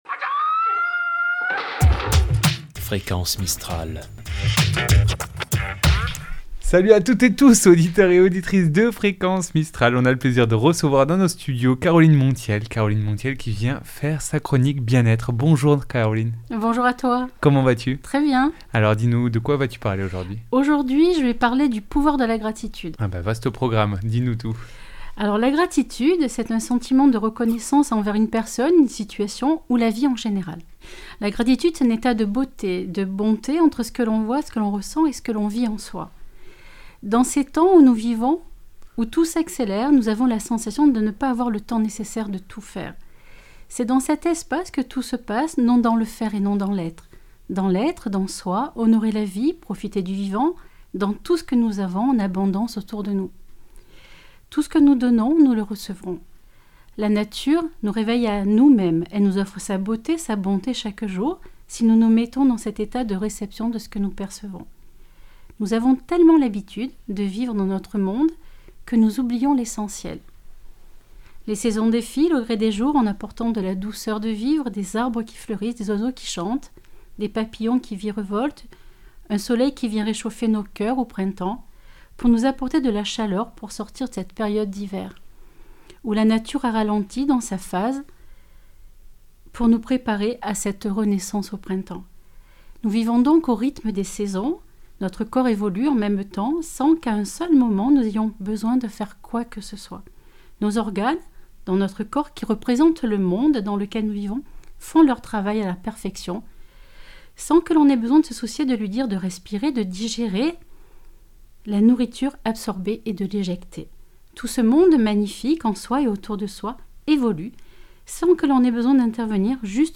Une chronique bien-être